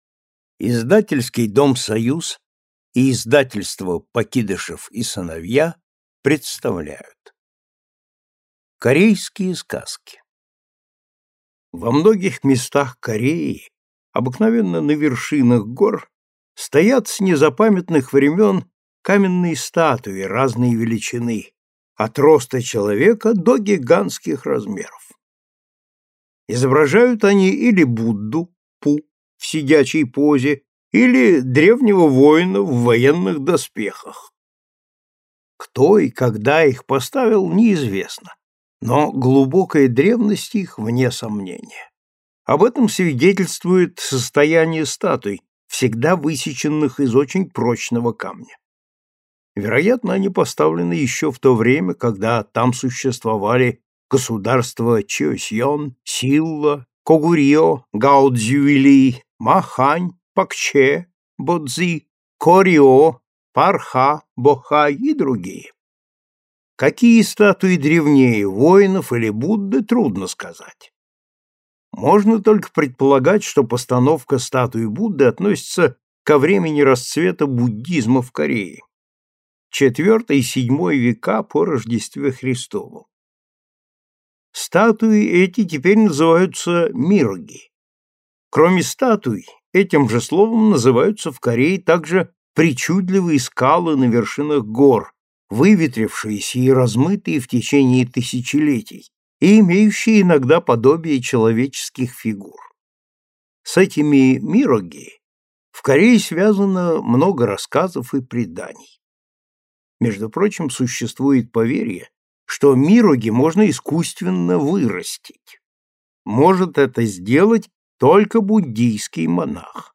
Аудиокнига Корейские сказки | Библиотека аудиокниг